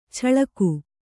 ♪ chaḷaku